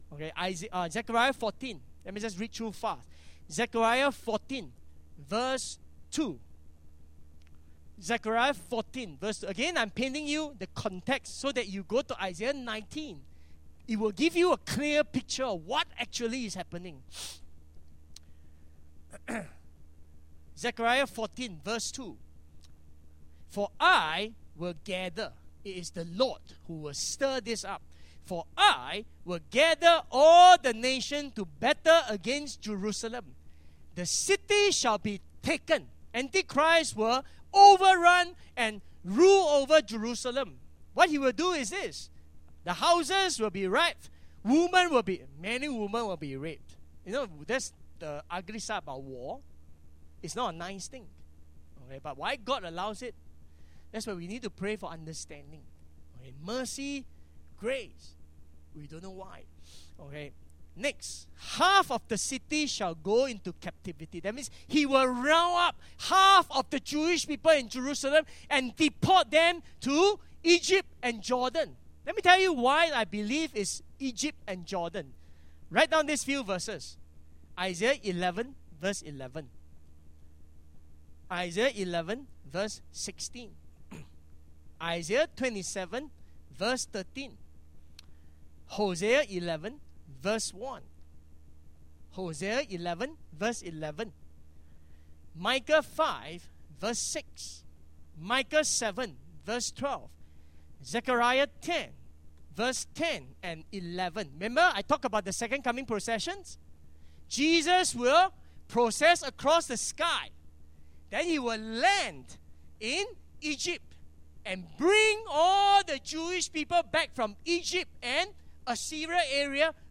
Listen to one of our past teachings below on Isaiah 19 - Understanding the Purpose of God for Egypt.